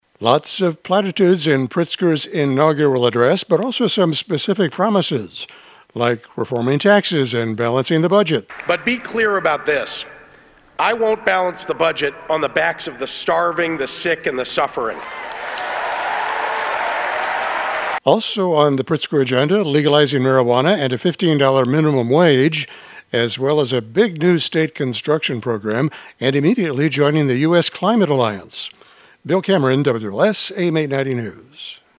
He took the oath at noon today in front of a big crowd in the Springfield convention center.